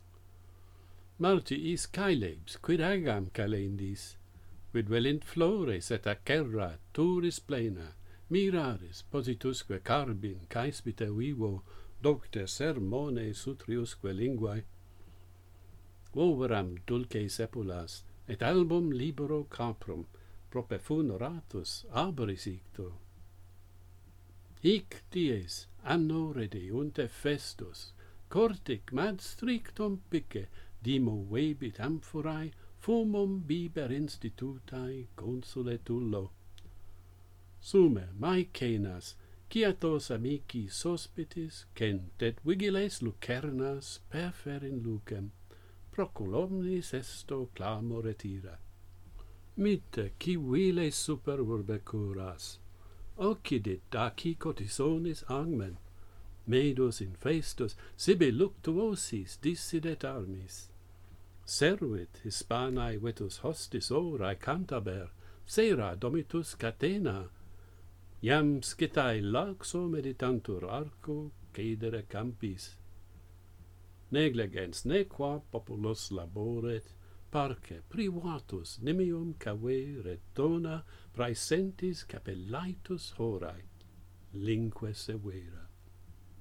Metre: Sapphic